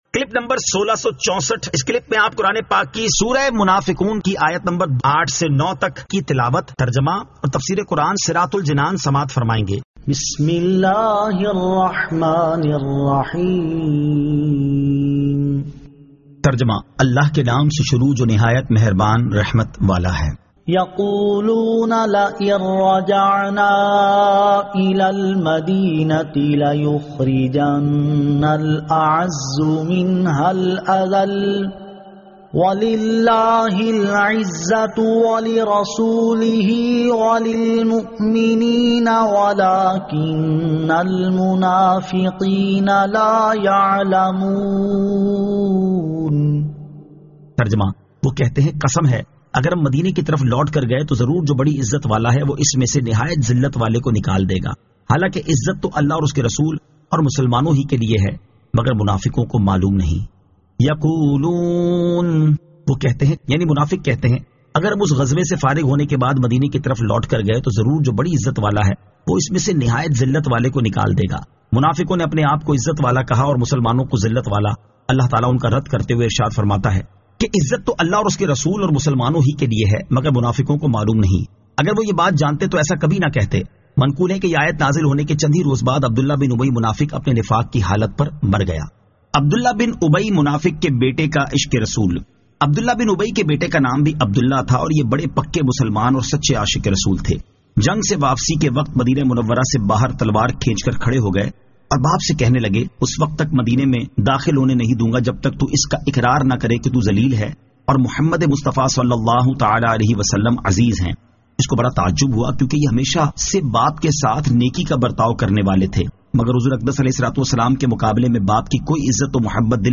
Surah Al-Munafiqun 08 To 09 Tilawat , Tarjama , Tafseer